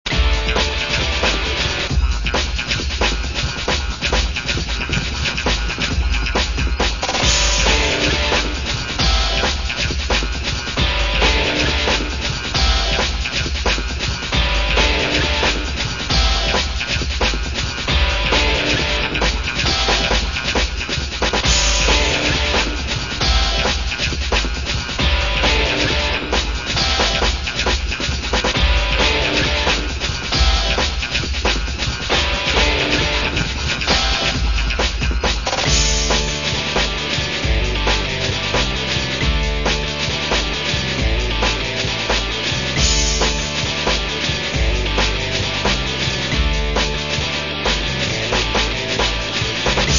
Tercera maqueta con estilo bailable y ritmos rápidos.